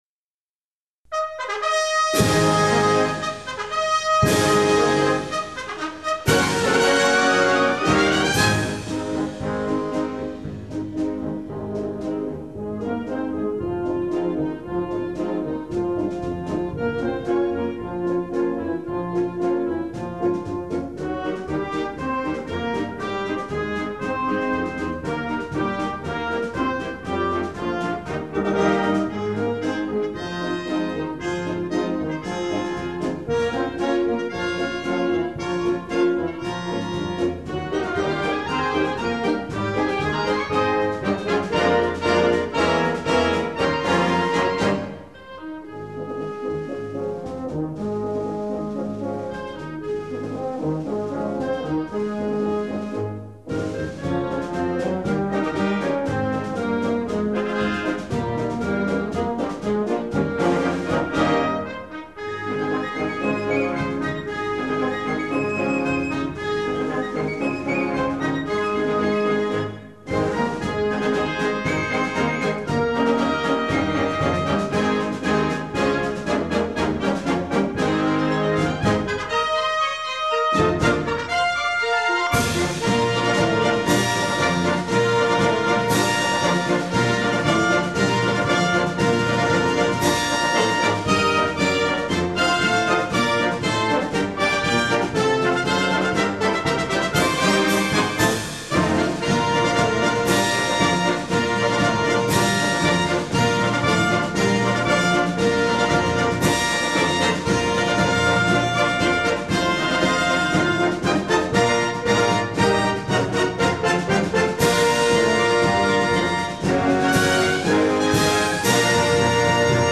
The band is from Fort McPherson, GA.
Compact disc recording